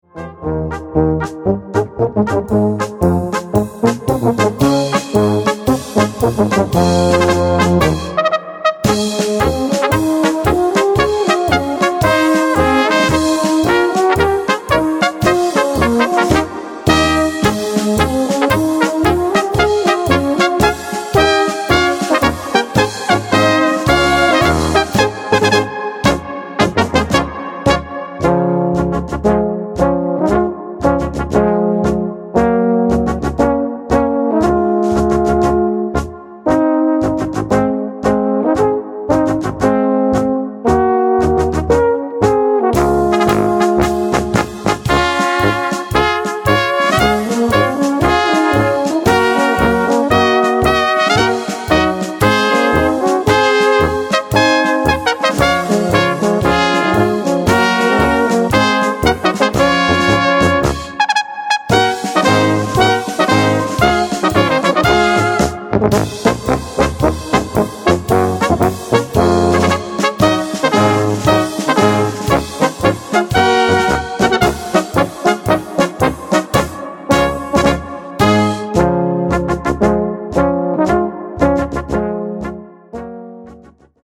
Gattung: Marsch
A4 Besetzung: Blasorchester Tonprobe